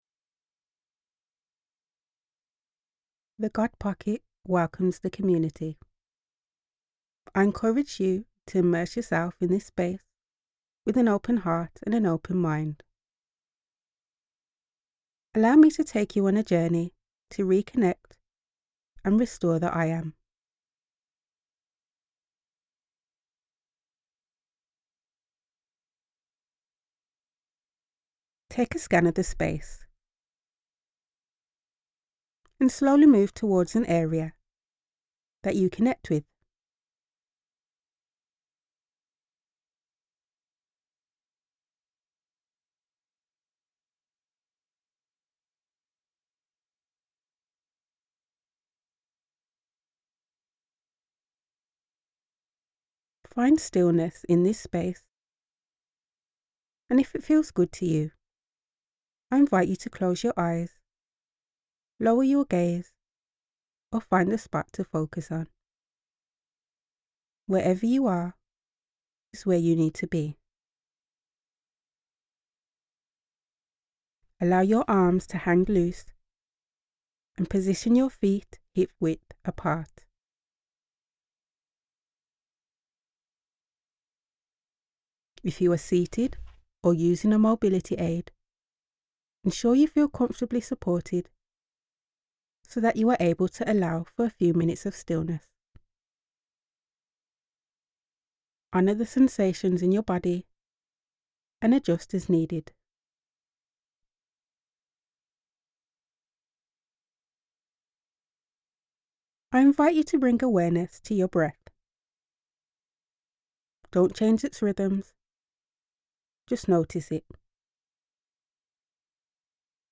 guided meditation, The Path of Sankofa